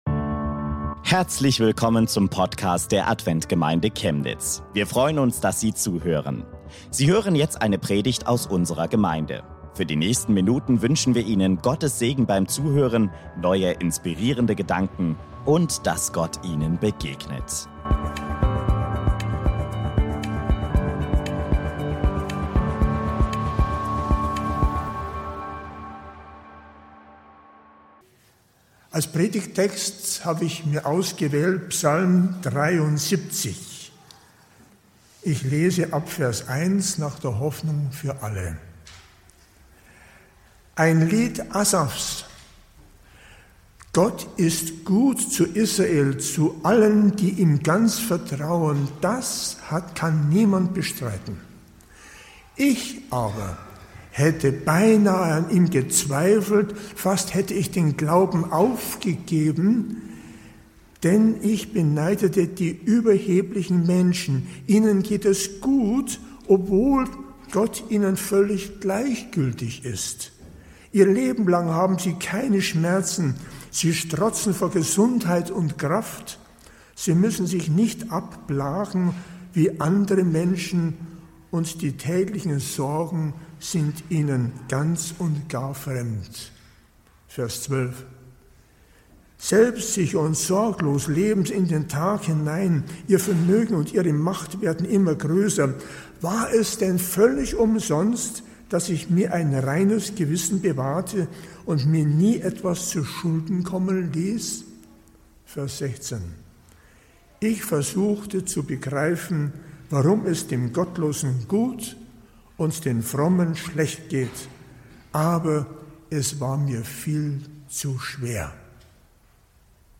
Gott ist uns nah und will uns Hoffnung schenken - auch im Leid ~ Adventgemeinde Chemnitz - Predigten Podcast